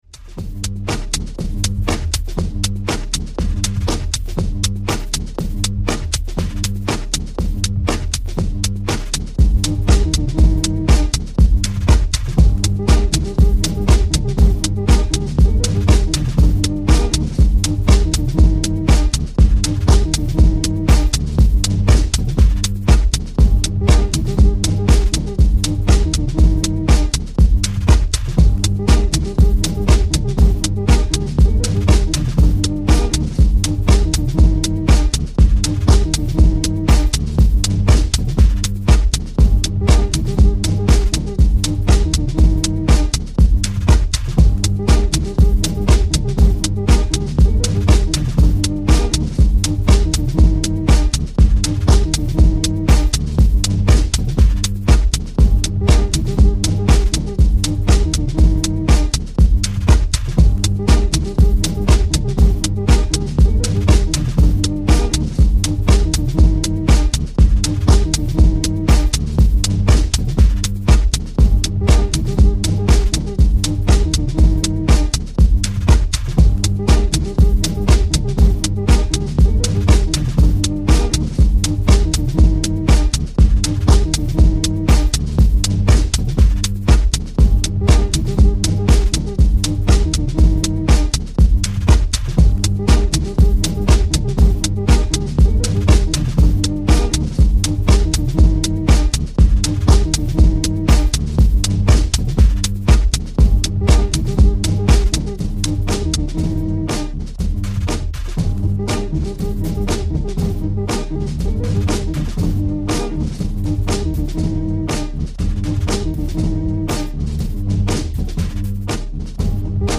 Amazing hypnotic banger!
fresh banging edits